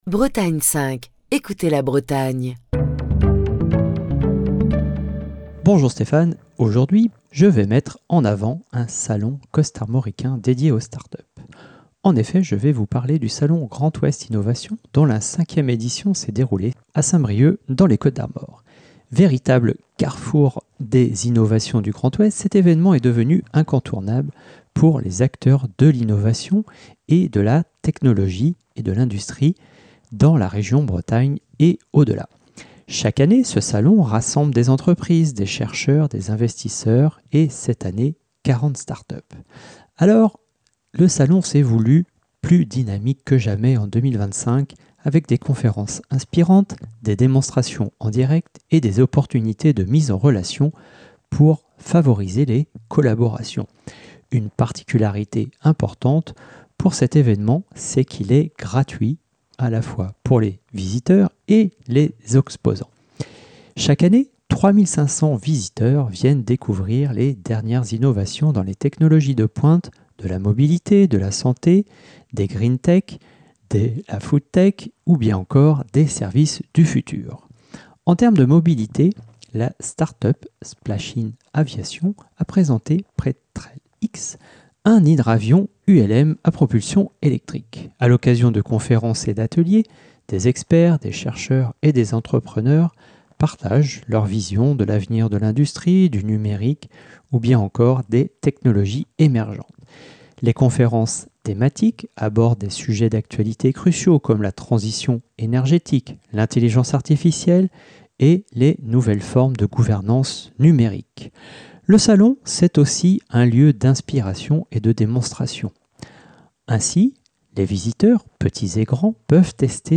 Chronique du 5 mars 2025.